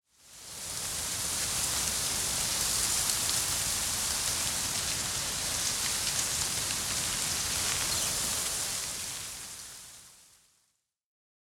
windtree_2.ogg